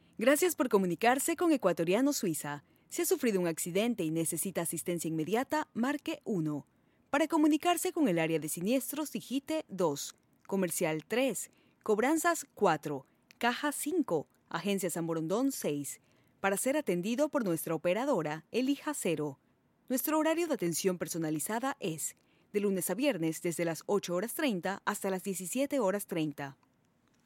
Locuiones de todo tipo: publicidad, doblaje, centrales telefĂłnicas (conmutador), documentales, etc. Acento neutro.
Sprechprobe: Industrie (Muttersprache):